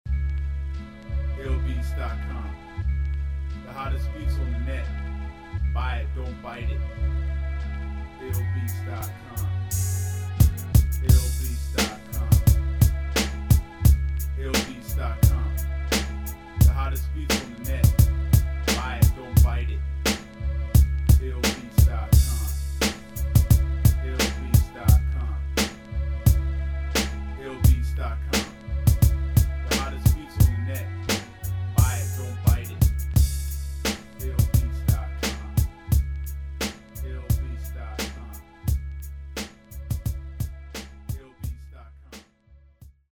East Coast Rap Beats